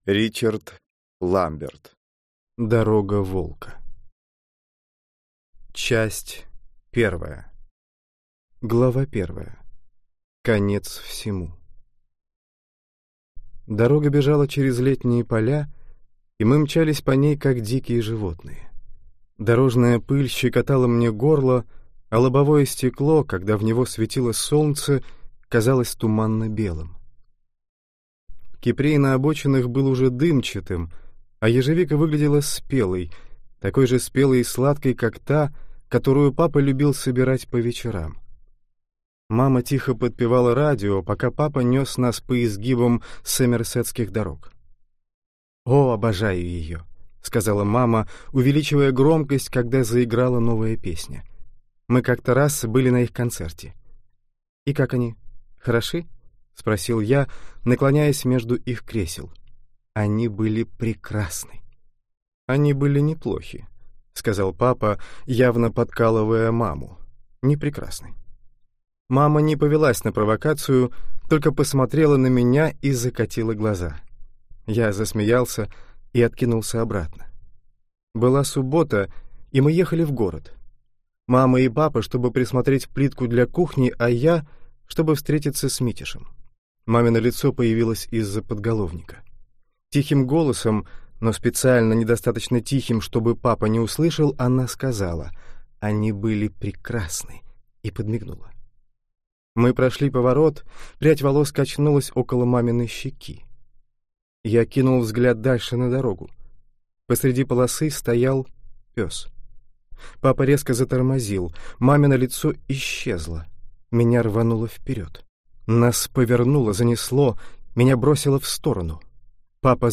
Аудиокнига Дорога волка | Библиотека аудиокниг